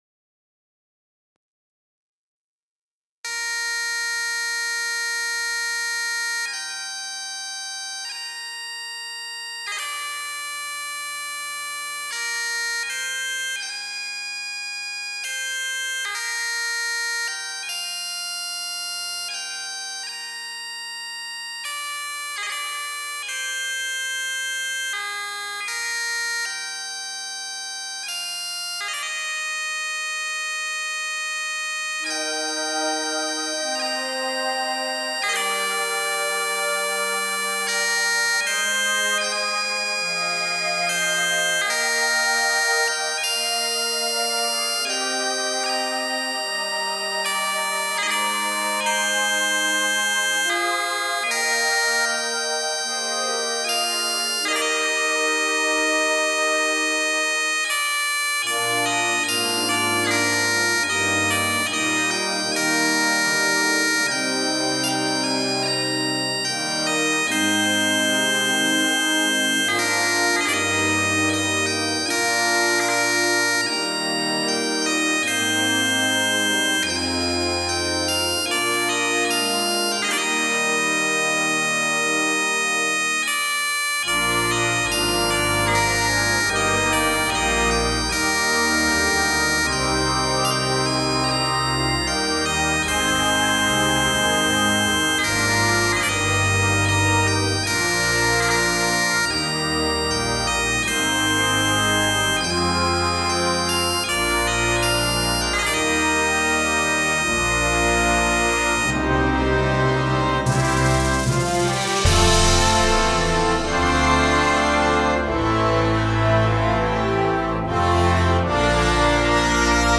CategoryConcert Band & Bagpipes
Timpani
Tubular Bells